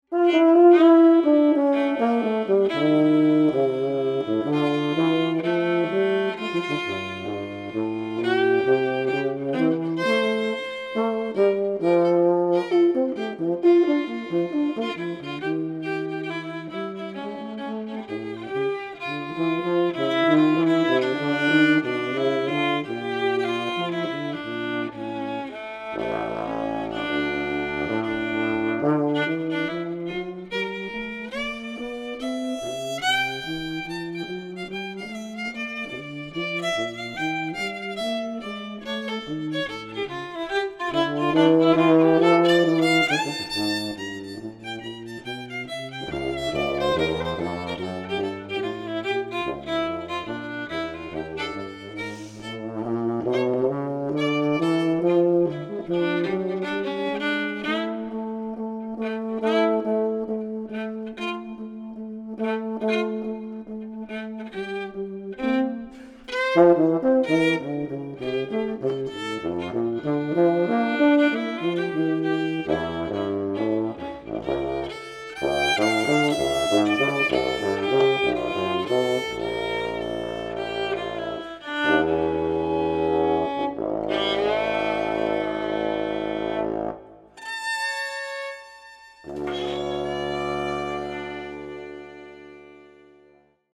violin, accordion, viola, guitar, & vocals
French horn, electric bass